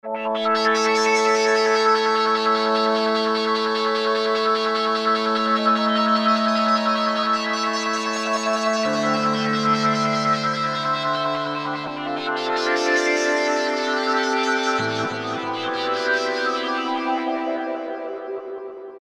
D14 - Motion Pad Beautiful evolving pad